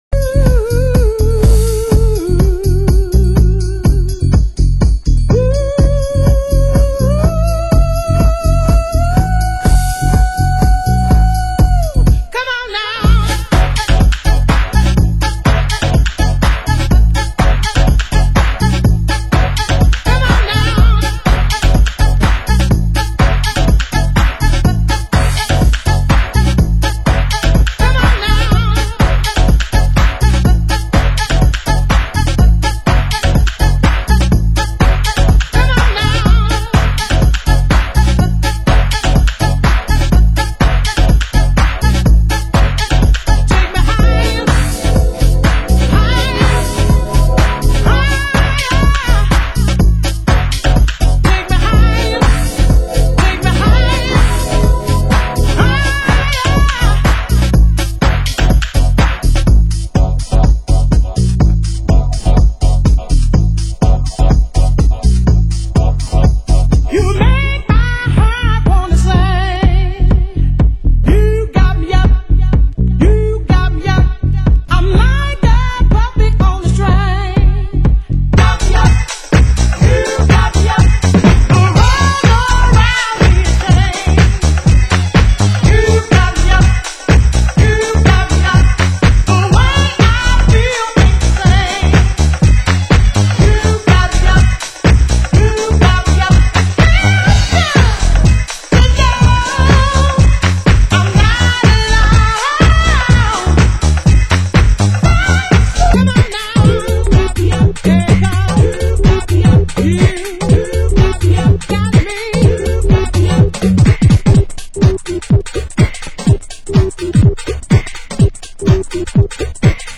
Format: Vinyl 12 Inch
Genre: Chicago House